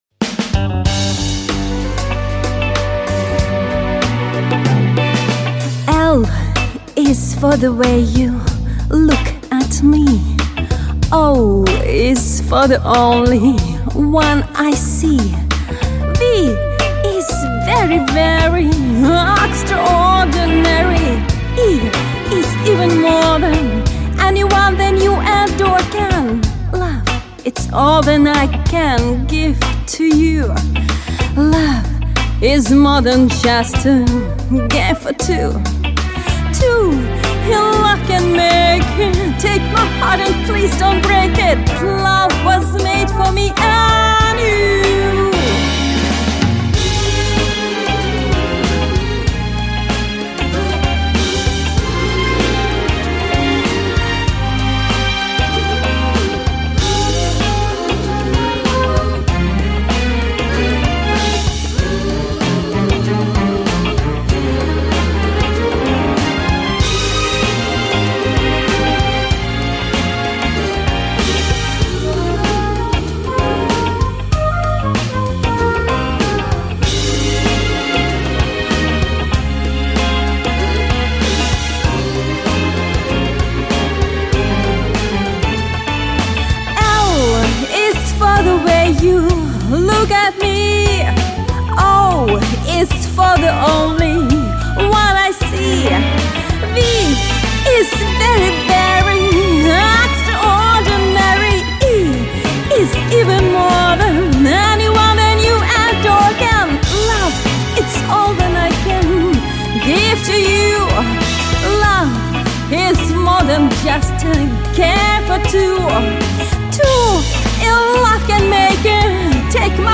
Женский
- Вы услышите классический джаз в живом звуке